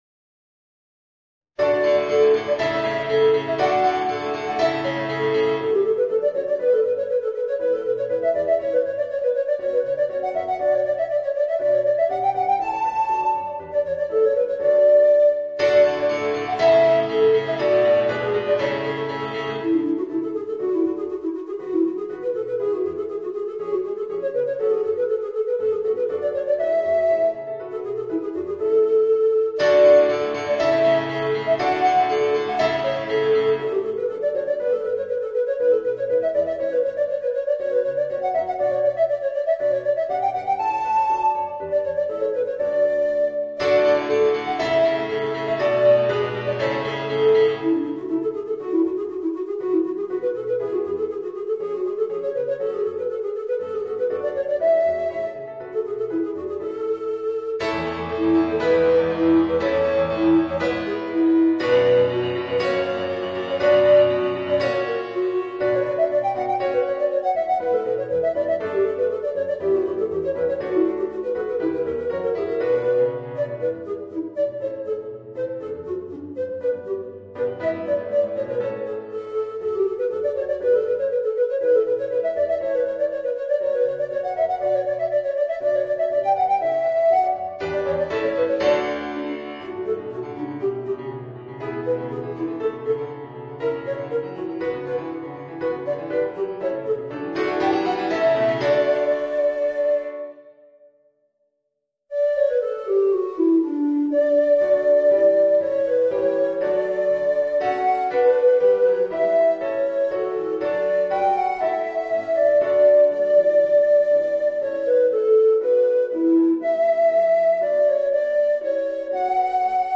Voicing: Soprano Recorder and Organ